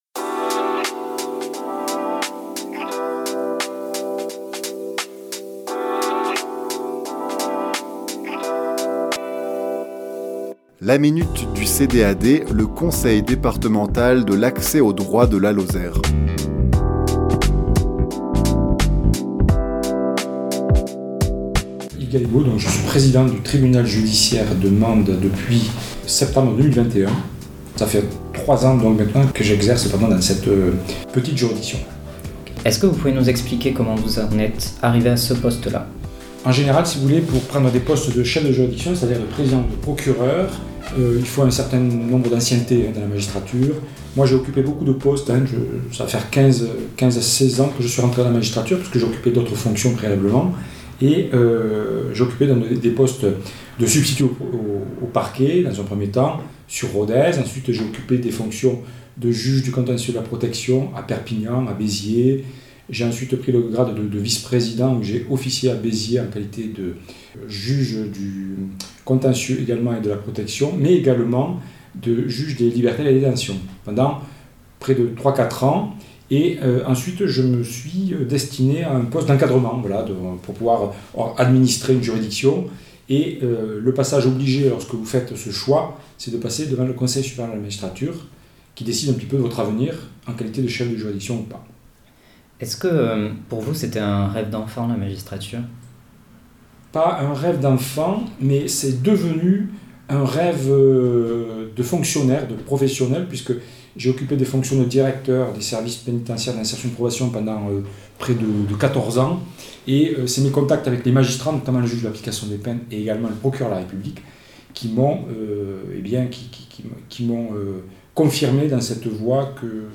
Chronique diffusée le lundi 6 janvier à 11h00 et 17h10